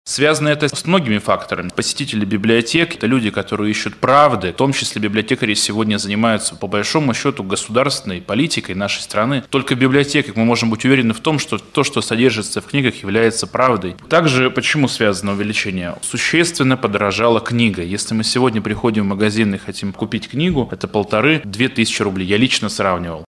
Среди причин — существенное подорожание книг. Также свердловчане приходят в библиотеки, чтобы получить достоверную информацию. Об этом заявил заместитель министра культуры региона Роман Дорохин на пресс-конференции «ТАСС-Урал».